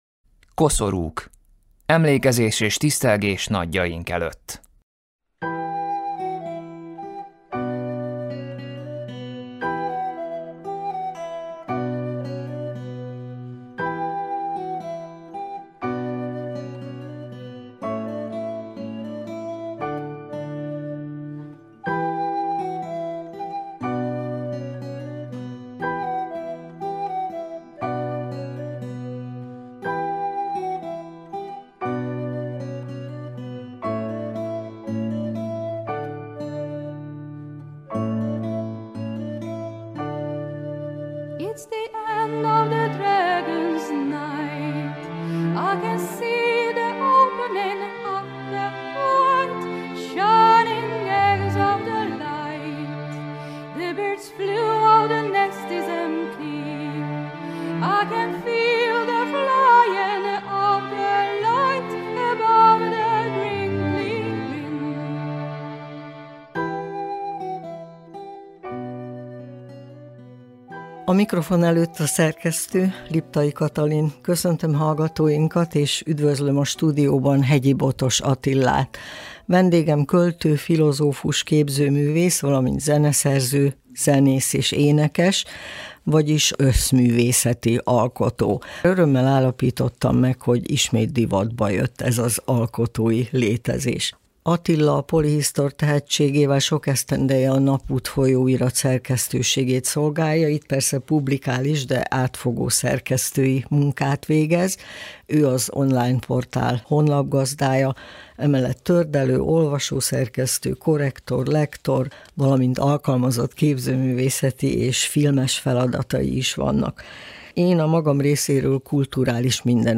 Interjú, beszélgetés április 27th